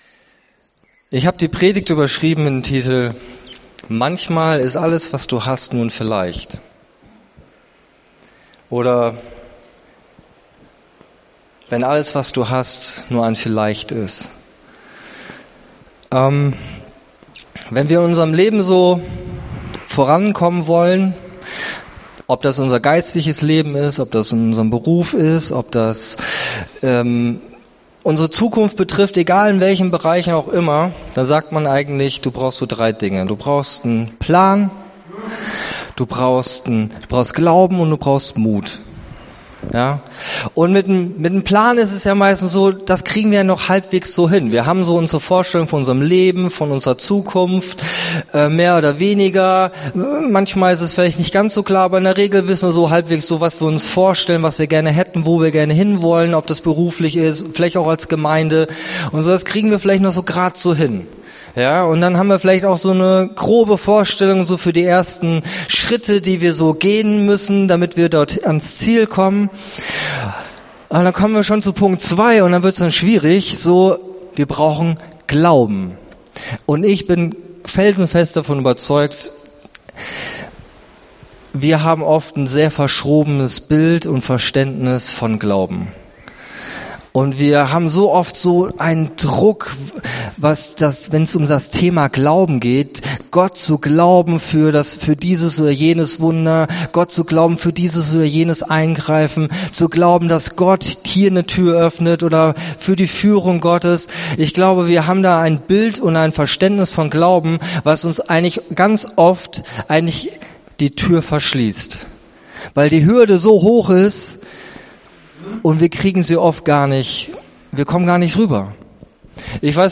Bibeltext zur Predigt: 1.Samuel 14,8